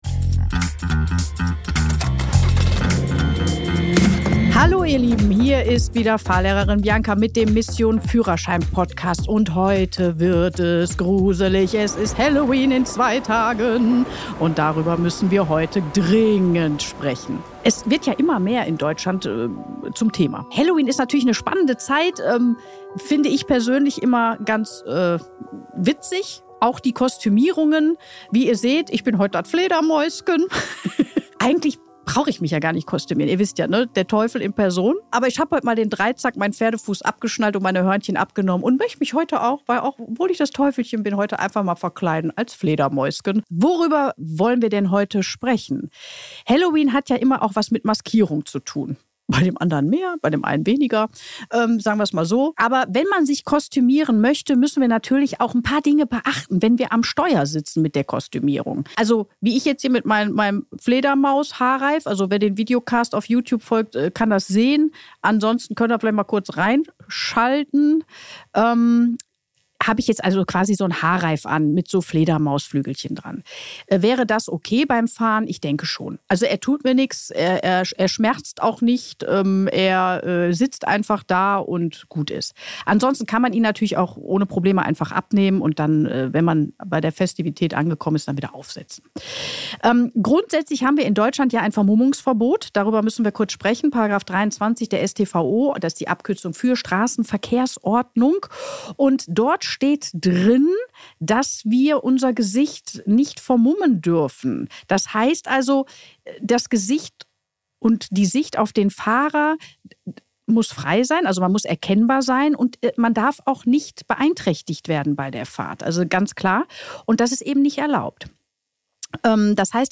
In dieser schaurig-schönen Solo-Episode vom Mission Führerschein Podcast spreche ich passend zu Halloween über ein Thema, das oft unterschätzt wird: Kostüme am Steuer – was ist erlaubt, was gefährlich und was kann dich deinen Führerschein kosten?